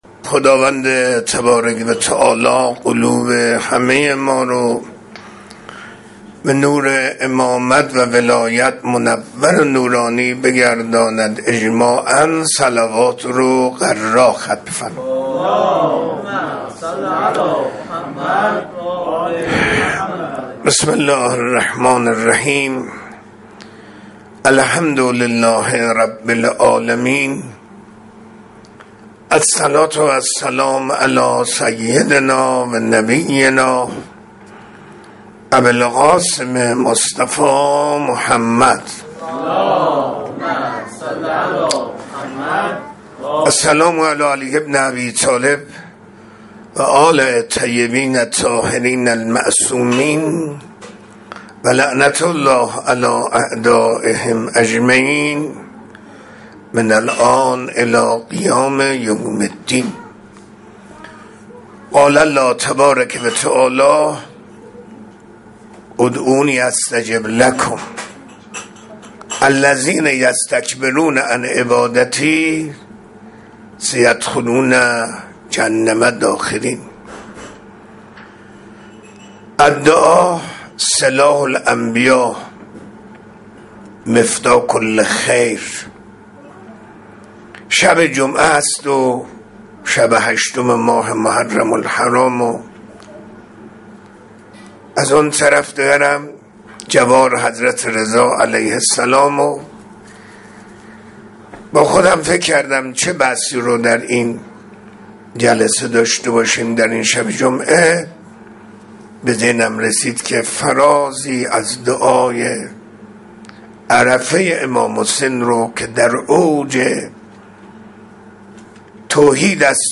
منبر 12 خرداد 1404